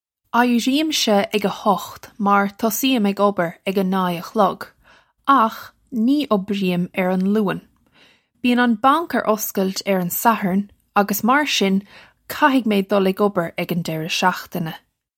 Eye-ree-im-sha ig uh hukht marr tuss-ee-im ig ubber ig uh nay uh khlug, akh nee ubbree-im air un Loo-un. Bee-un un bawnk air osskilt urr un Sahern uggus mar shin kahig may dull ig ubber ig un jerra shokhtina.
This is an approximate phonetic pronunciation of the phrase.